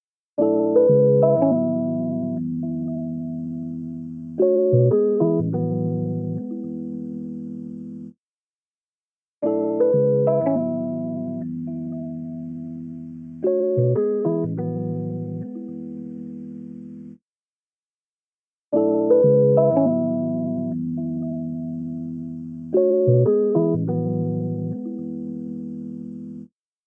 EQ65 | Synth | Preset: Warmer
EQ65-Warmer.mp3